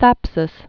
(thăpsəs)